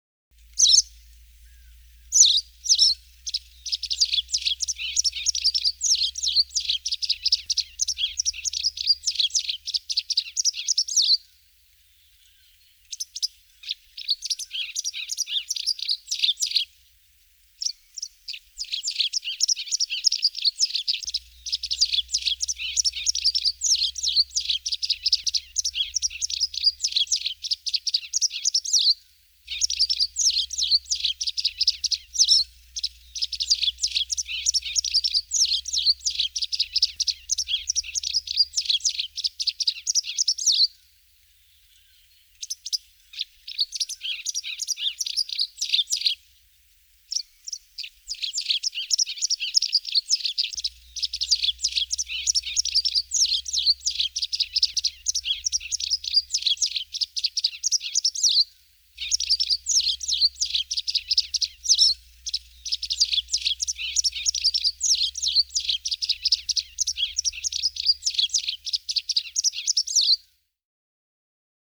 Bachstelze Gesang
• Der Gesang ist kurz, klar und flötend, häufig zu hören beim Revierflug.
Typisch ist ein schnelles „zi-zi-zi“ oder „tschick“, oft beim Fliegen zu hören.
Bachstelze-Gesang-Voegel-in-Europa.wav